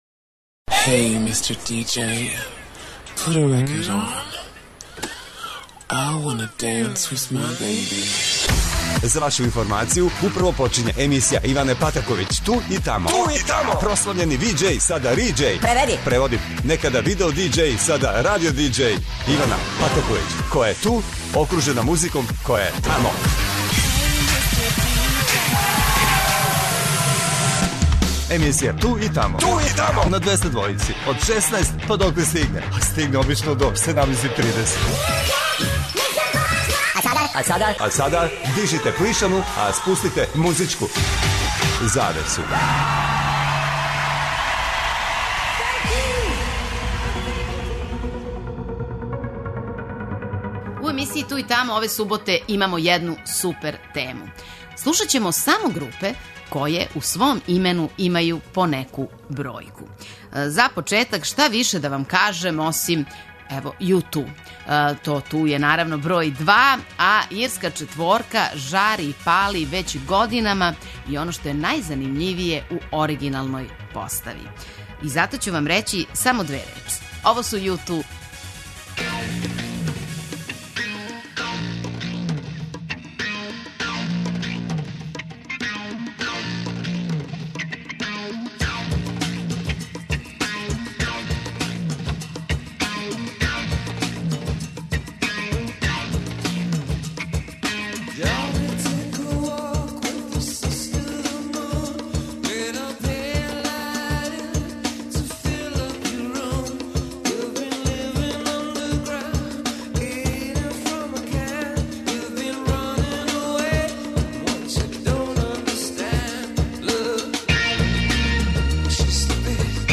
У новој емисији све се врти око бројева. Шифра је наравно 202, а слушаоцима смо припремили супер музички пакет у ком су бендови који имају бројке у свом имену.
Очекују вас велики хитови, страни и домаћи, стари и нови, супер сарадње, песме из филмова, дуети и још много тога.